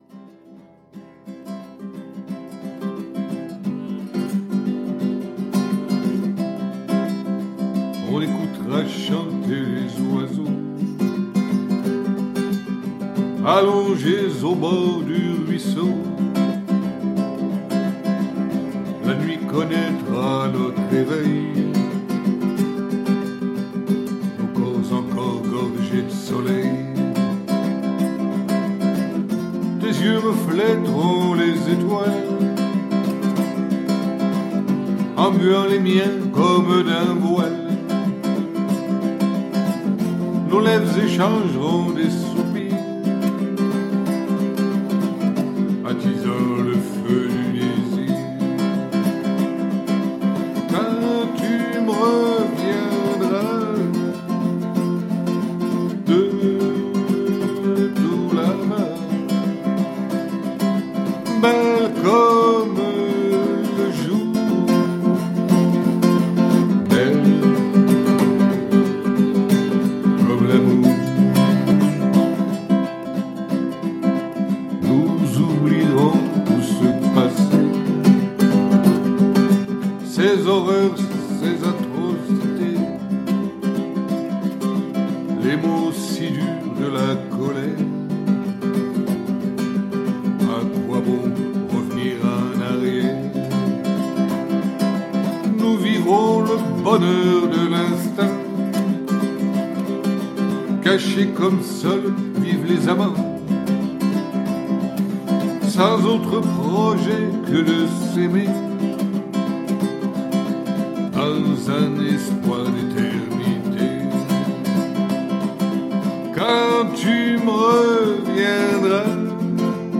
Glamour